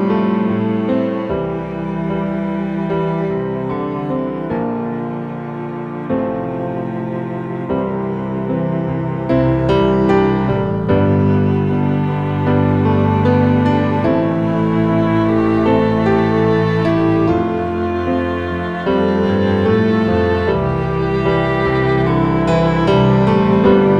Down 4 Semitones